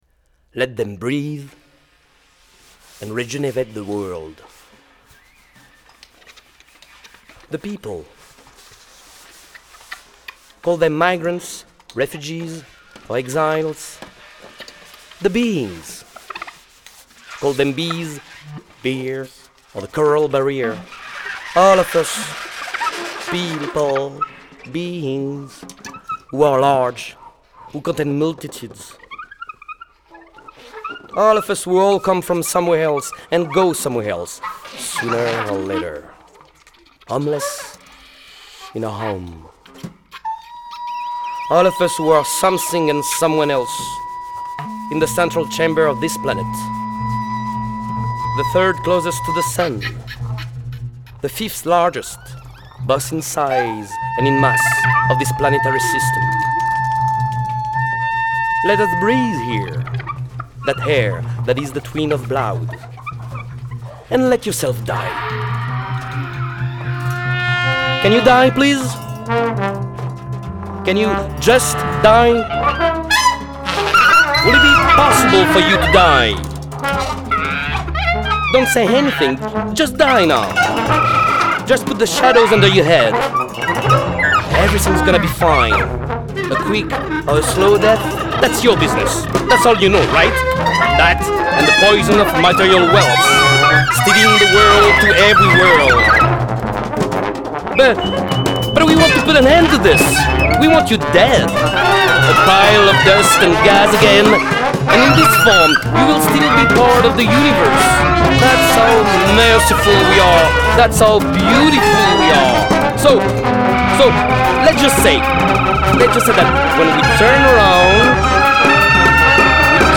clarinet
piccolo trumpet
trombone
drums
Recorded in New Orleans, LA.